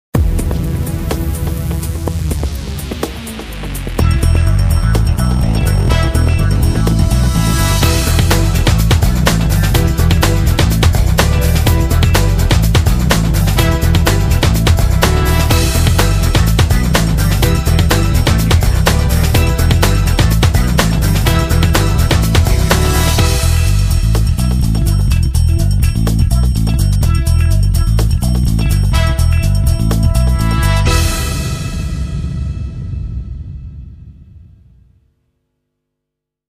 music logo